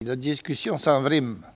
parole, oralité
Enquête Arexcpo en Vendée
Catégorie Locution